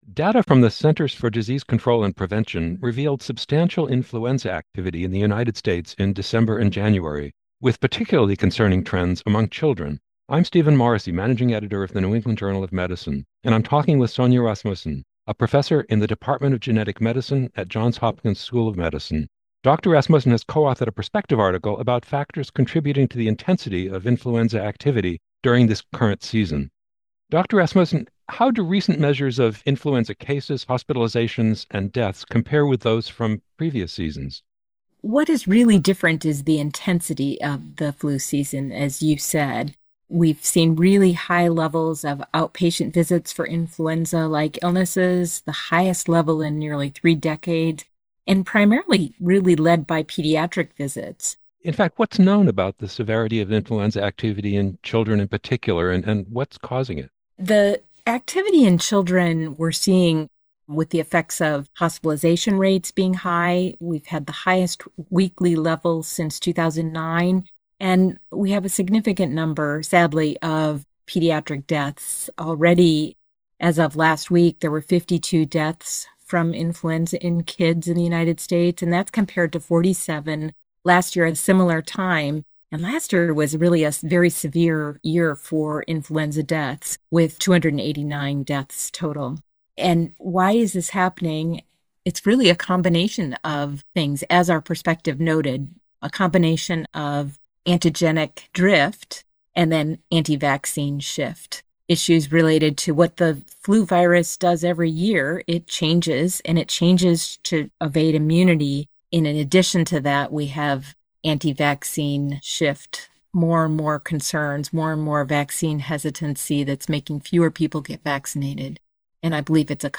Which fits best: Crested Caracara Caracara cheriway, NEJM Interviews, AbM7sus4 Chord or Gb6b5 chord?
NEJM Interviews